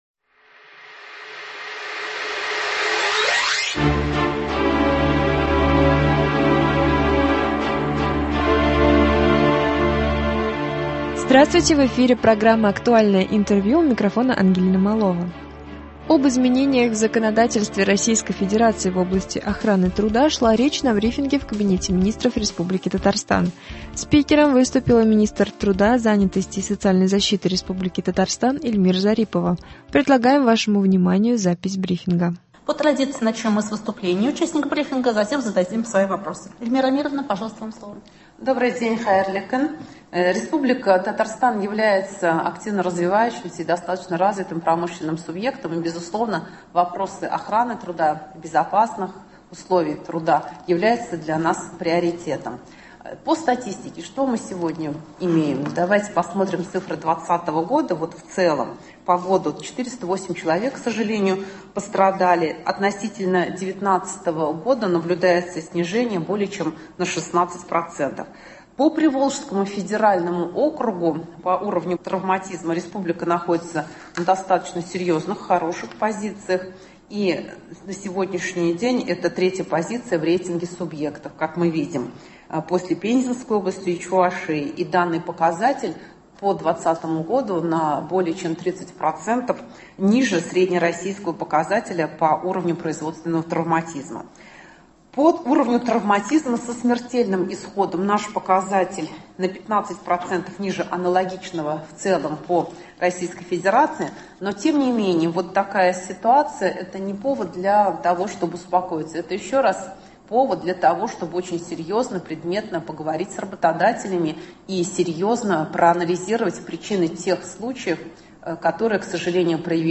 Об изменениях в законодательстве Российской Федерации в области охраны труда шла речь на брифинге в Кабинете Министров РТ. Спикером выступила министр труда, занятости и социальной защиты Республики Татарстан Эльмира Зарипова.